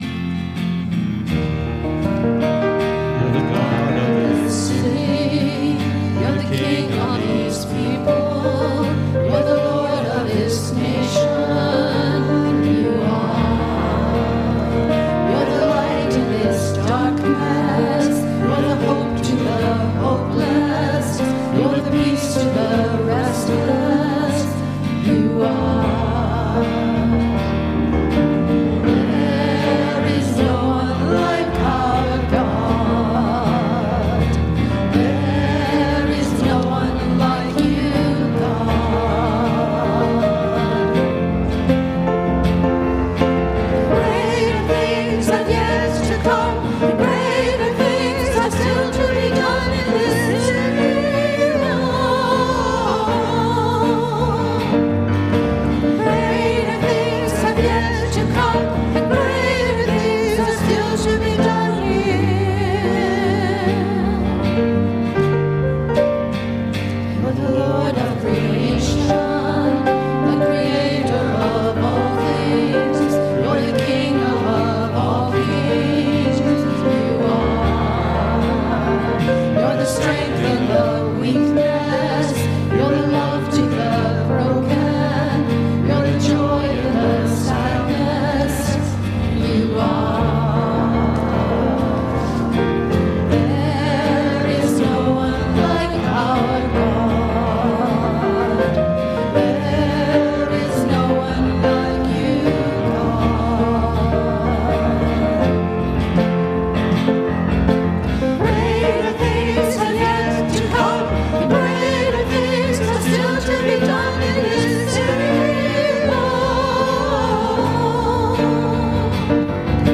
WORSHIP - 10:30 a.m. Last after Epiphany